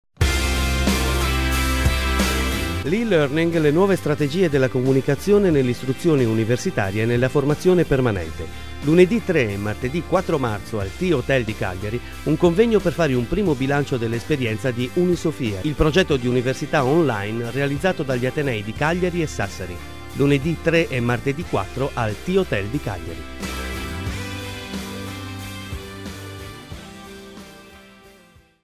spot radio